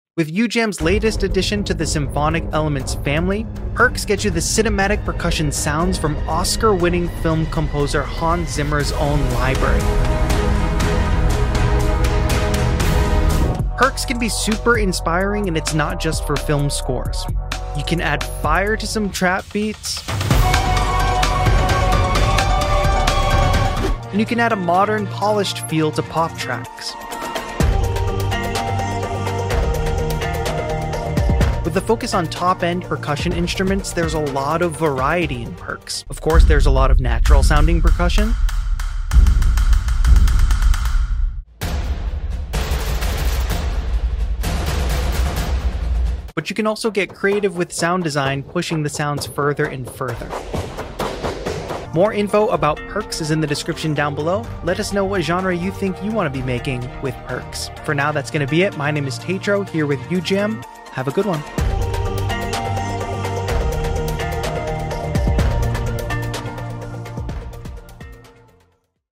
Introducing PERRCS: The latest orchestral